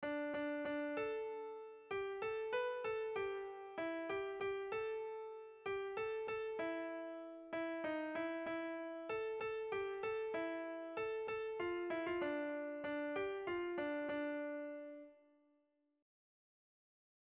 Erromantzea
Kopla handia
AB